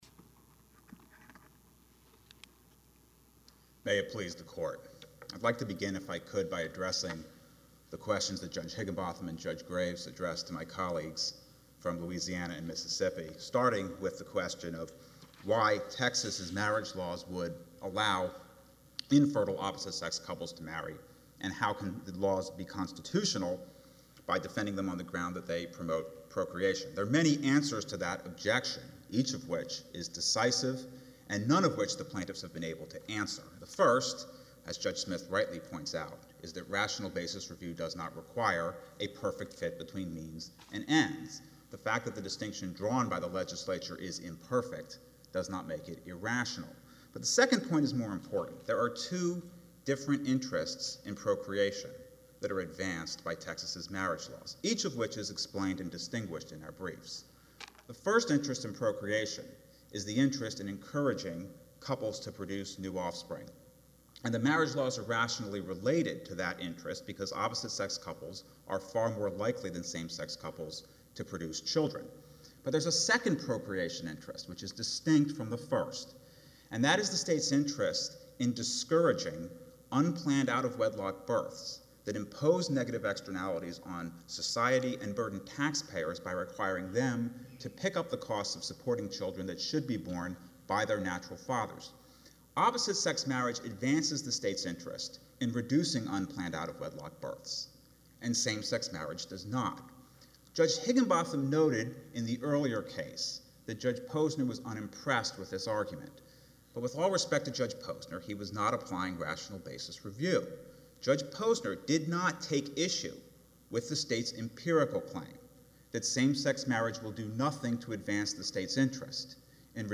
5th Circuit Hears Oral Arguments In 3 Same-Sex Marriage Cases
Yesterday, the U.S. Fifth Circuit Court of Appeals heard oral arguments in three same-sex marriage cases.